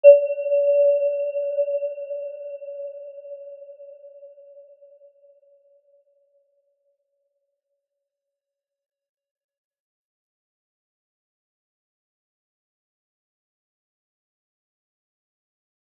Aurora-E5-mf.wav